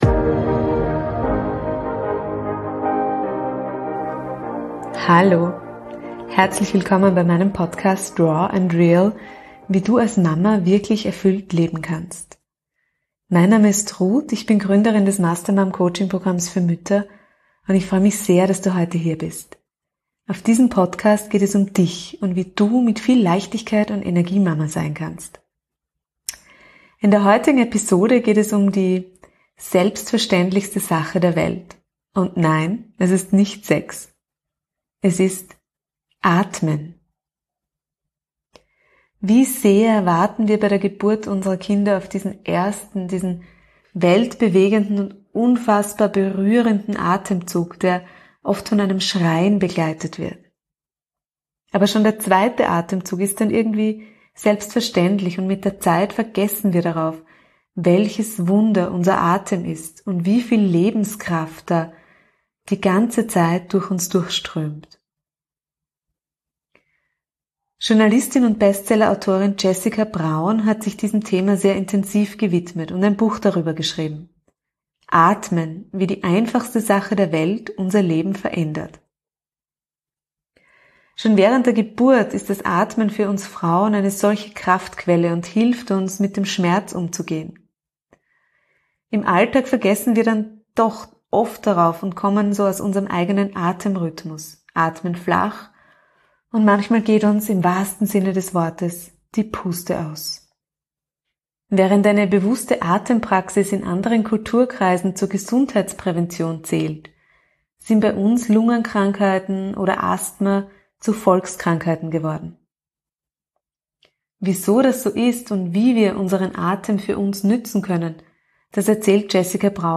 #96 Die selbstverständlichste Sache der Welt: Atmen. Interview